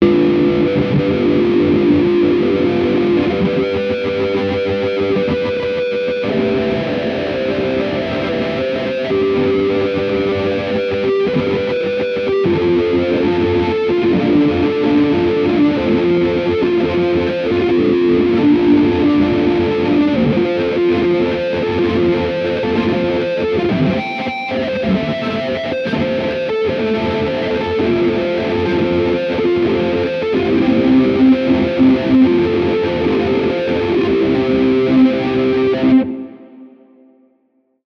sunrise tailgate (intense)